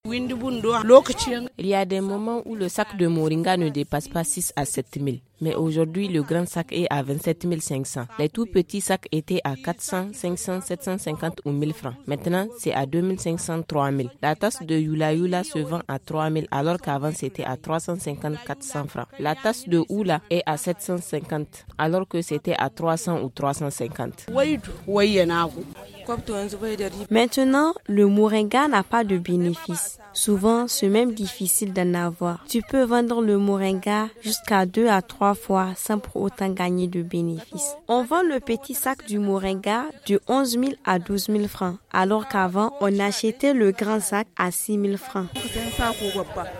FR Micro trottoir https